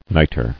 [ni·tre]